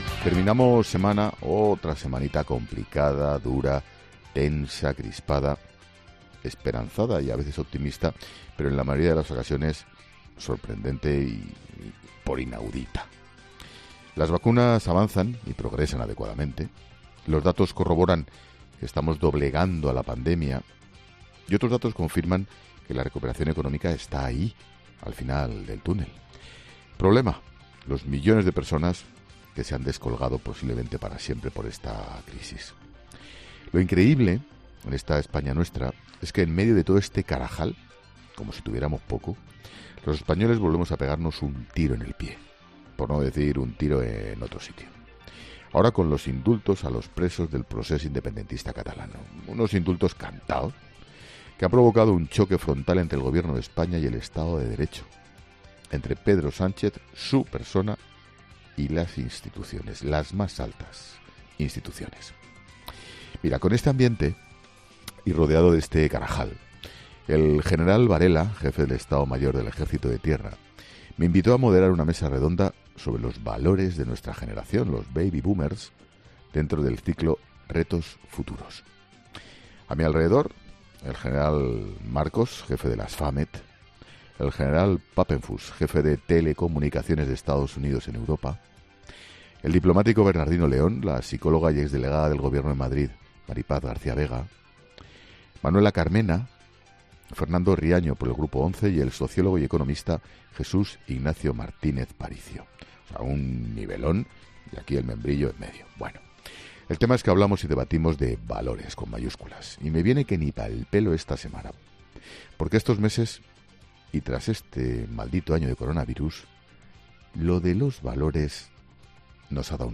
Monólogo de Expósito
El director de 'La Linterna', Ángel Expósito, reflexiona en su monólogo tras su vivencia durante la moderación de una mesa redonda en la Escuela de Guerra